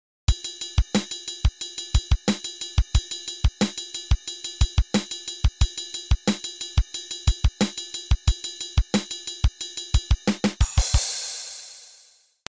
The origins of this groove are lost in time. I’ve been playing it for years, whenever a slow or half-time section in an arena-rock anthem comes along.